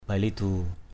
/baɪ-lɪ-d̪u:/ (d.) một loại gấm. patri cuk aw bailidu pt} c~K a| =bl{d~% công chúa mặc áo gấm.
bailidu.mp3